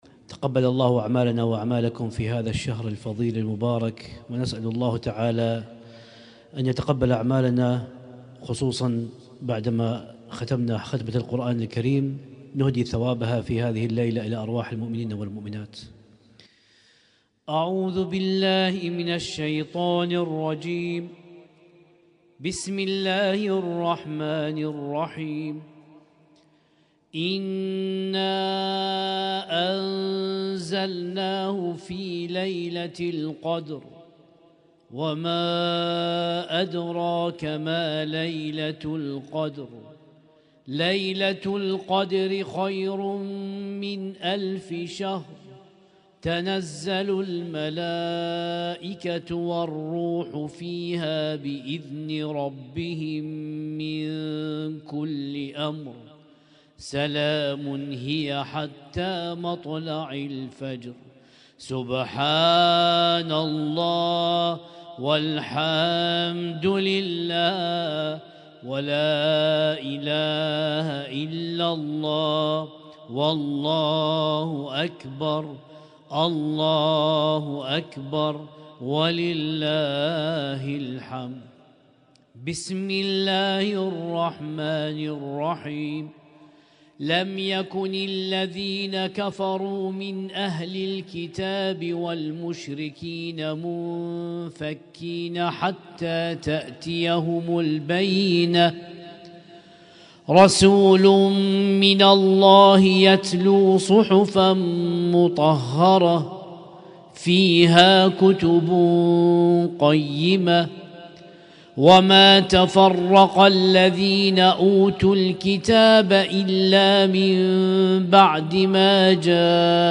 اسم التصنيف: المـكتبة الصــوتيه >> القرآن الكريم >> القرآن الكريم - شهر رمضان 1446